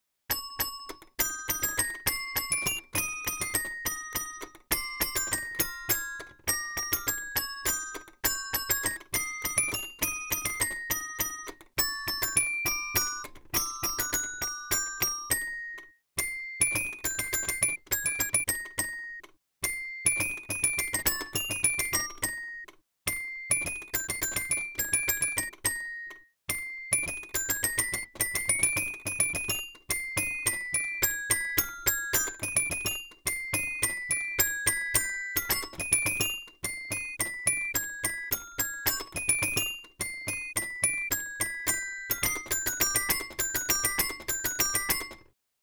This is a sampled toy piano called the Pianosaurus Rex.
The original toy had no sharps or flats.
Notes range from B3 to F4.
Recorded with Oktava MC012 stereo pair microphones plugged into Focusrite ISA preamps. 24/48 RME AD converters.Listen Here